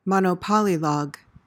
PRONUNCIATION:
(mon-uh-POL-i-log)